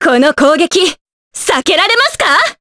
Veronica-Vox_Skill4_jp.wav